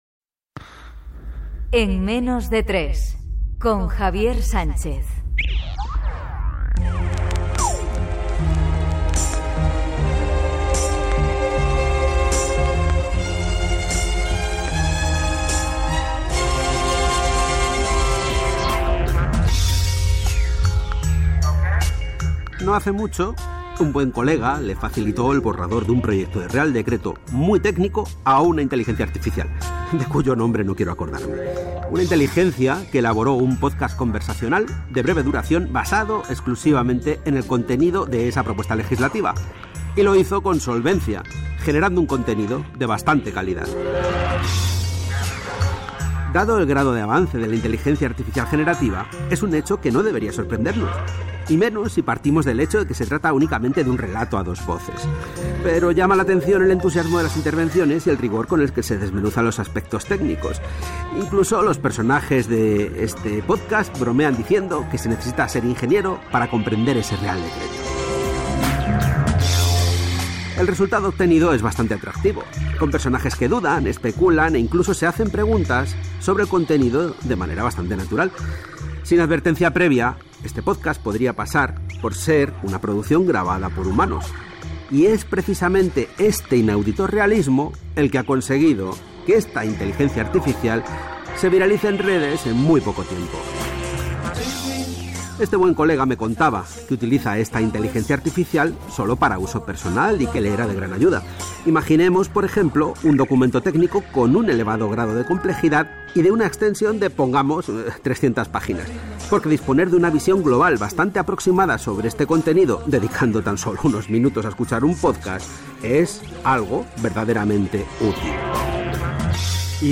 Careta del programa, espai dedicat a la intel·ligència artificial generativa i la creació de pòdcast de conversa a partir d'un text introduït
Divulgació